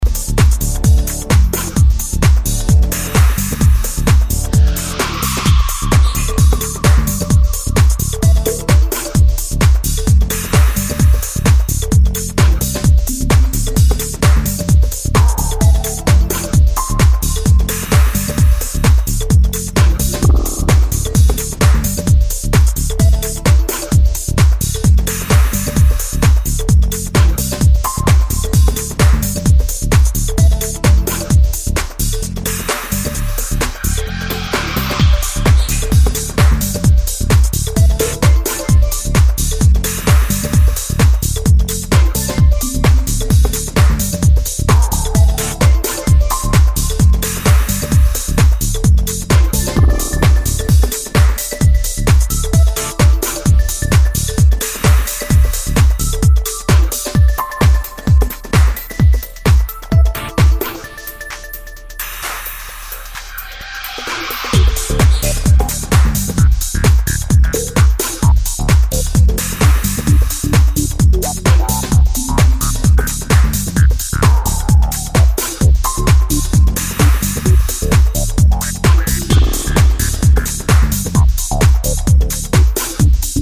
more electro-tinged territories than his previous material
modern house music